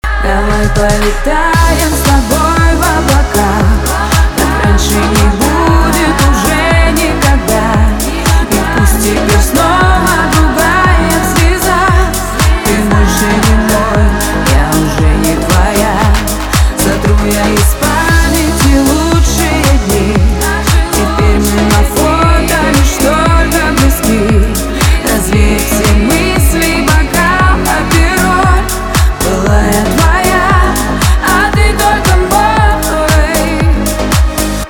поп
грустные , печальные